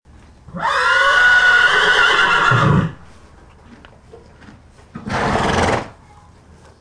Le cheval | Université populaire de la biosphère
il hennit, puis renâcle
cheval_2.mp3